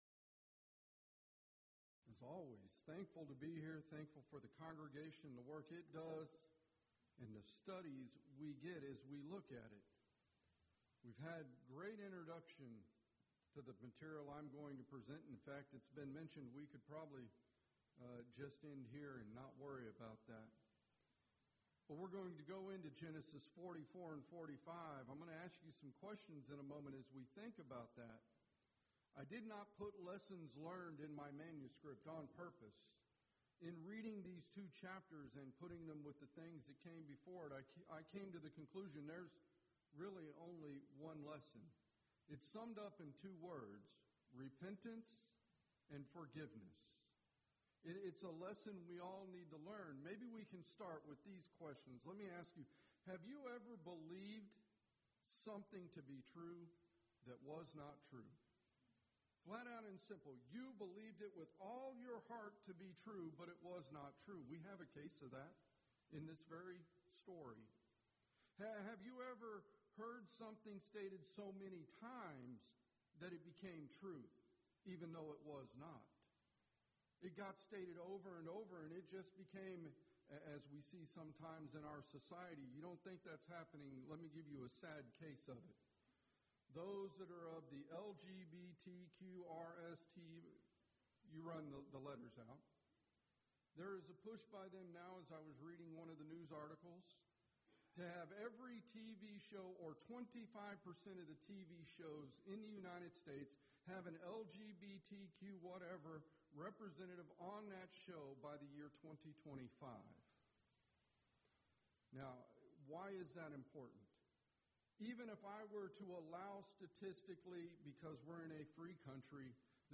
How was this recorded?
Series: Schertz Lectureship Event: 16th Annual Schertz Lectures